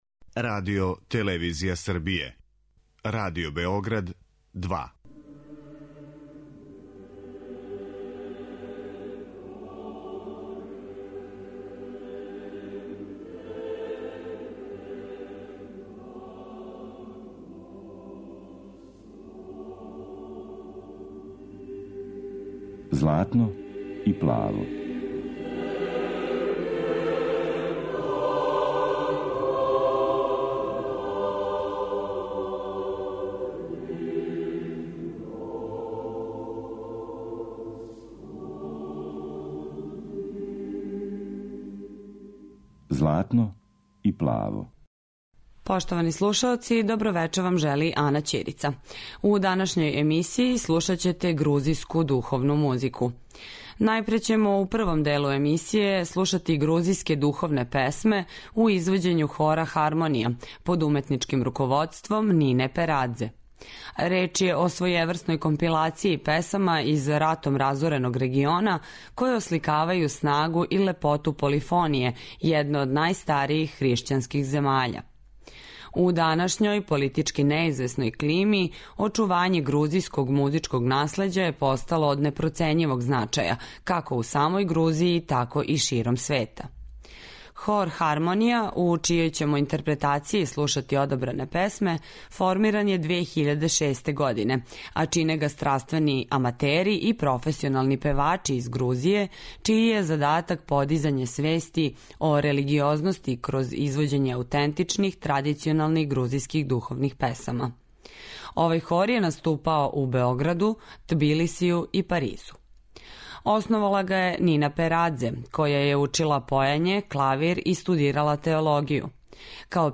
Емисија посвећена православној духовној музици